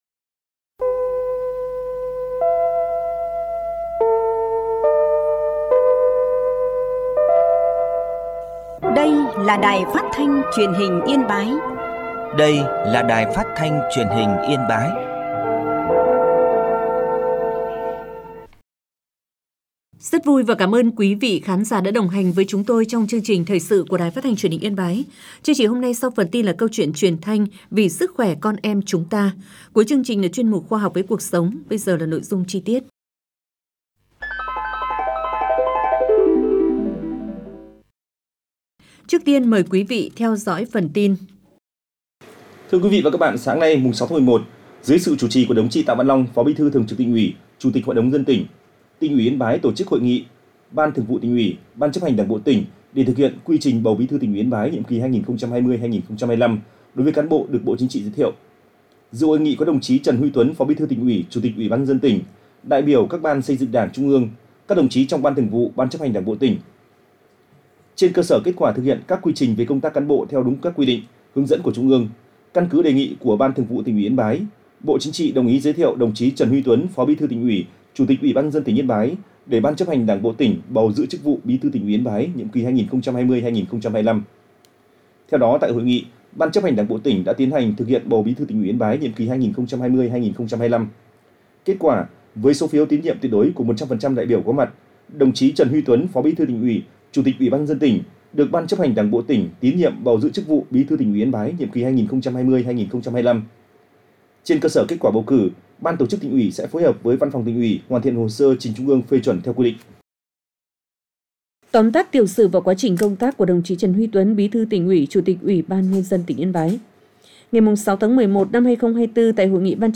Thoi_su_sang_07.mp3